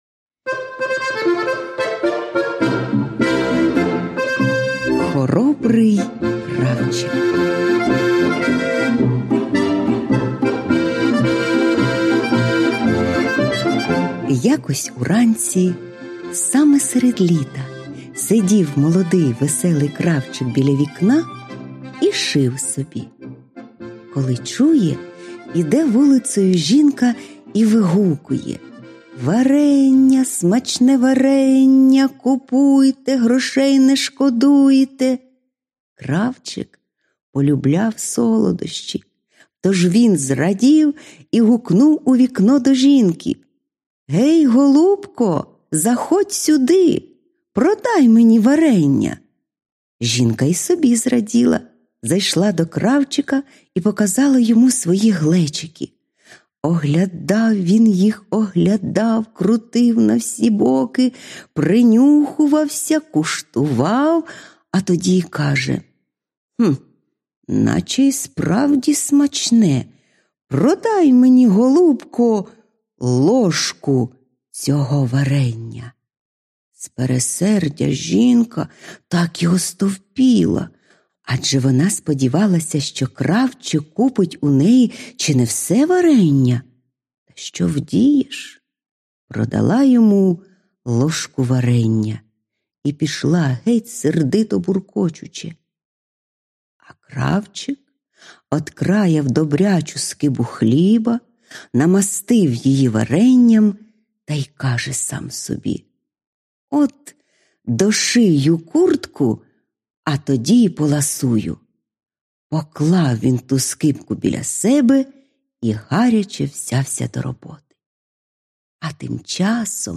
Аудіоказка Хоробрий кравчик